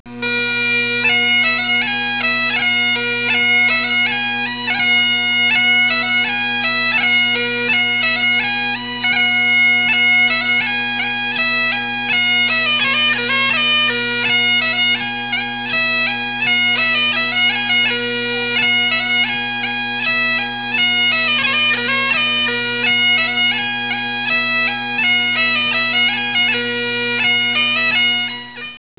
La cornemuse écossaise ou biniou braz (cornemuse grande) en breton
L'instrument à vent, composé d'une poche constituant une réserve d'air, de 3 bourdons (qui produisent un son continu), d'un lévriad (tuyau sur lequel on joue la mélodie), d'un sutel (tuyau dans lequel on souffle pour gonfler la poche).
cornemuse.wav